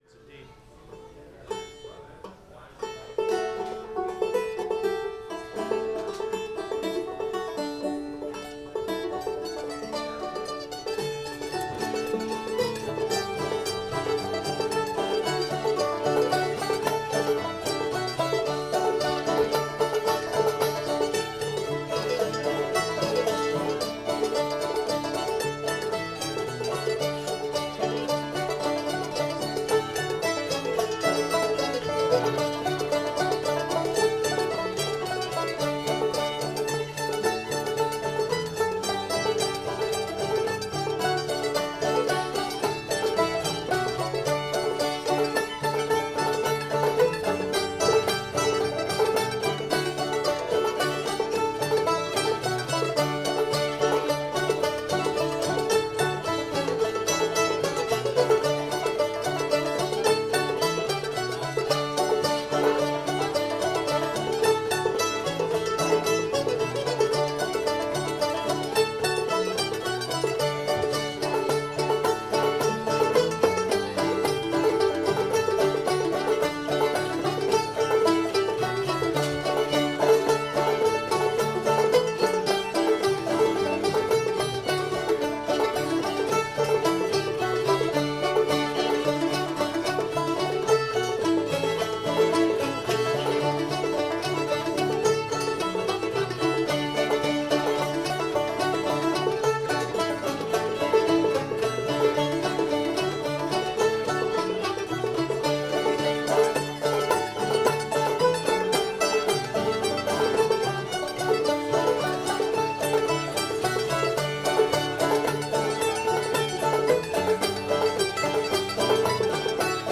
mississippi sawyer [D]